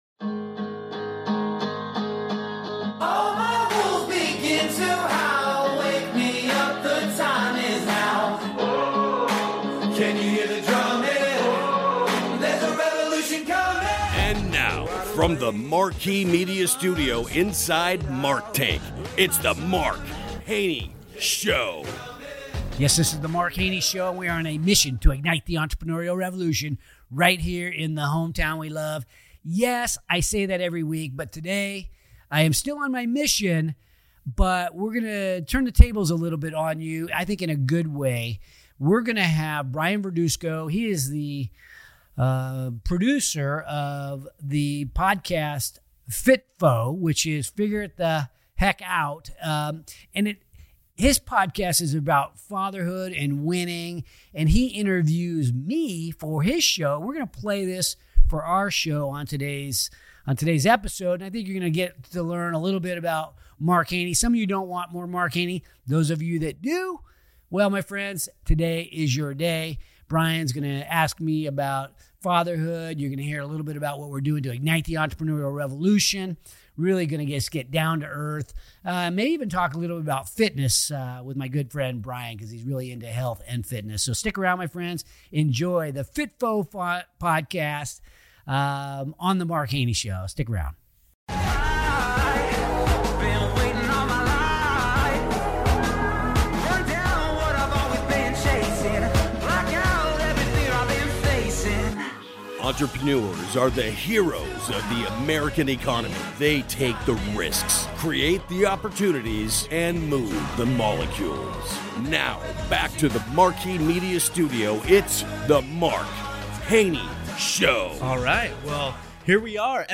Tune in for a fun and heartfelt discussion on a really worthy topic: Fatherhood.